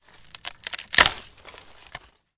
木栅栏的声音 " 啪嗒, 木栅栏, J
Tag: 栅栏 开裂 裂缝 开裂 断裂 木材 木方 木板 栅栏 折断 开裂 捕捉 围栏 围栏 打破